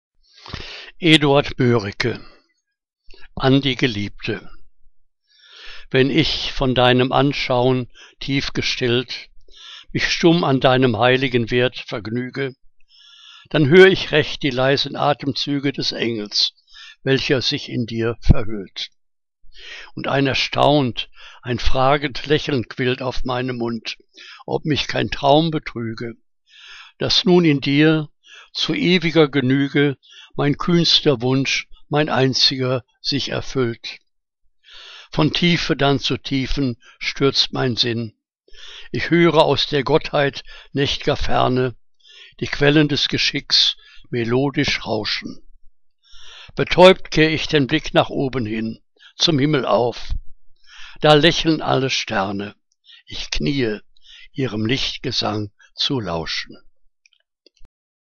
Liebeslyrik deutscher Dichter und Dichterinnen - gesprochen (Eduard Mörike)